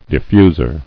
[dif·fus·er]